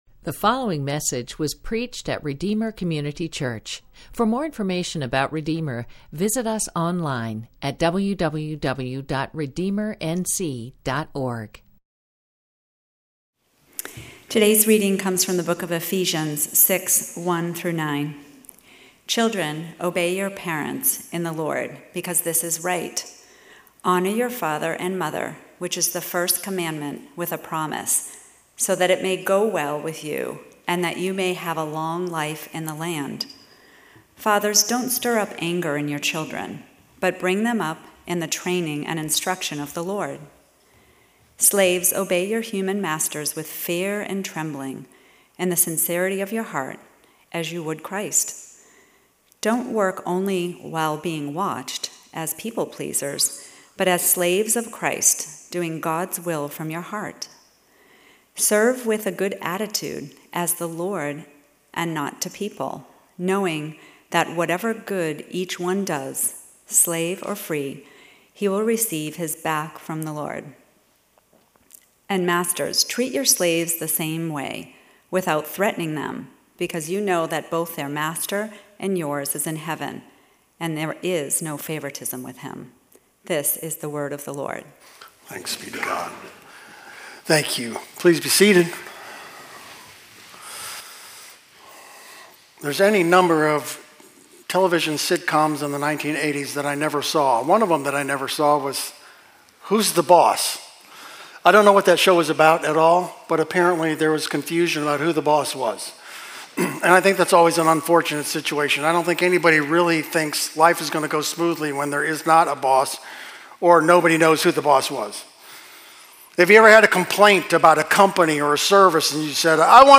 Sermons - Redeemer Community Church